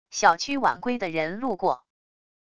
小区晚归的人路过wav音频